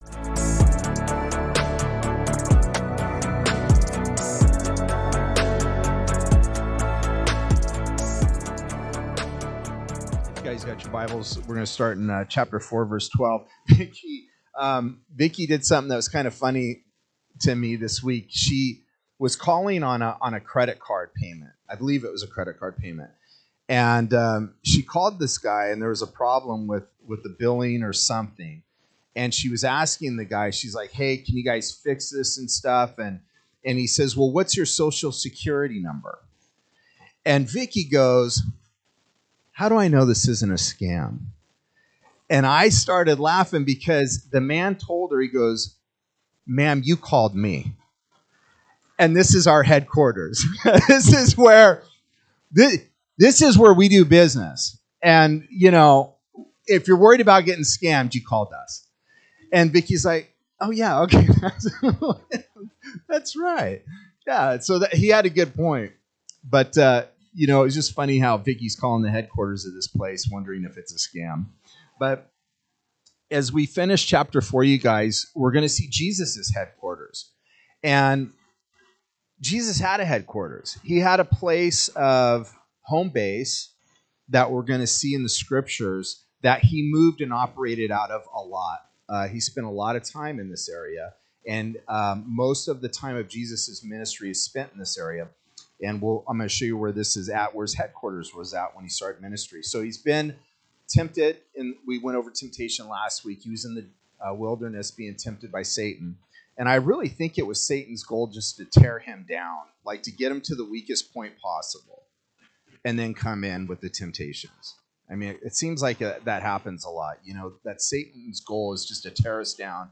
Sermons Archive - Page 3 of 47 - Ark Bible Church